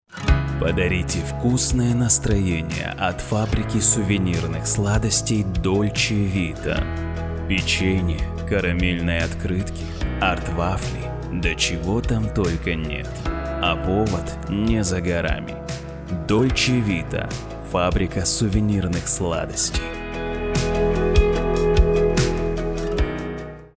Пример звучания голоса
Муж, Другая
Запись производится в студии, оборудование (Звук.карта, Микрофон, наушники- набор марки «Steinberg»).